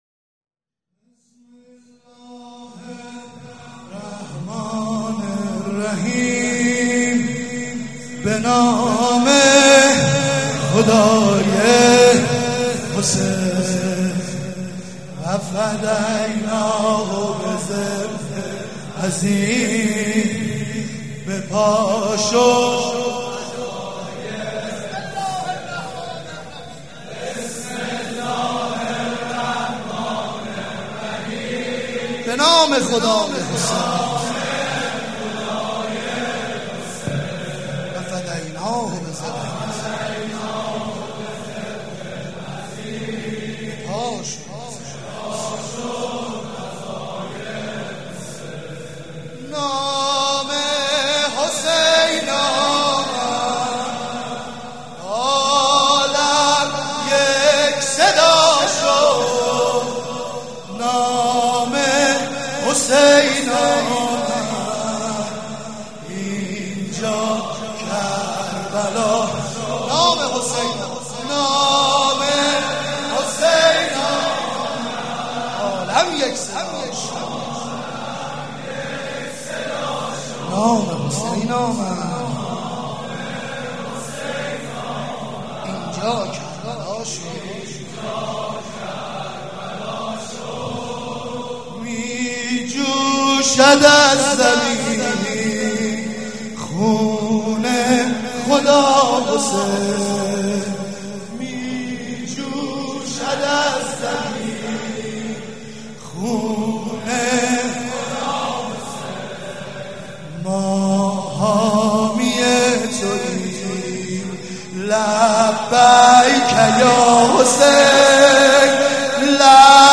نوحه
مداح
مراسم عزاداری شب تاسوعا